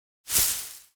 grass swish 3.ogg